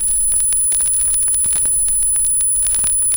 Stereo_Test_Tone_02.wav